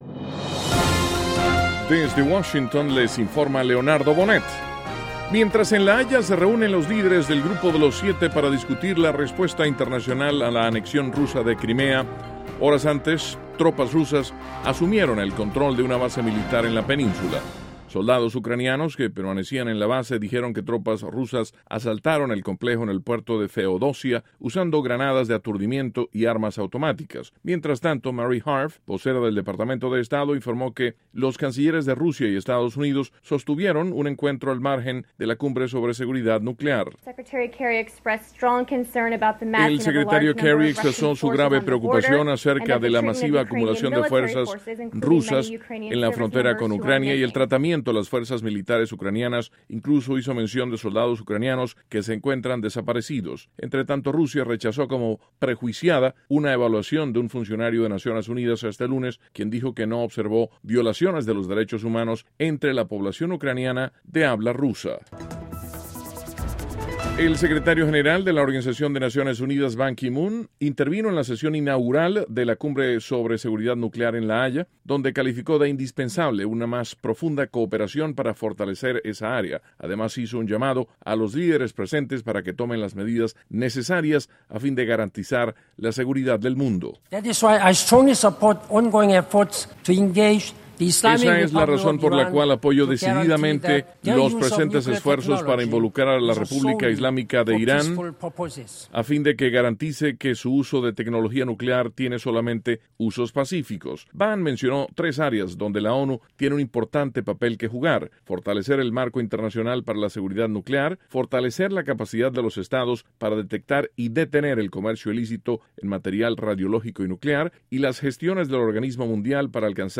Duración: 2:55 Contenido: 1.- Secretario de Estado Kerry se reúne con canciller ruso al margen de la Cumbre de Seguridad Nuclear. (Sonido Kerry) 2.- En la Cumbre de Seguridad Nuclear, en La Haya, el secretario general de la ONU, Ban Ki Moon, insta a una más profunda cooperación en materia nuclear. (Sonido Ban) 3.- Autoridades en Seattle confirman desaparición de 108 personas como consecuencia de un deslave. (Sonido – Superviviente)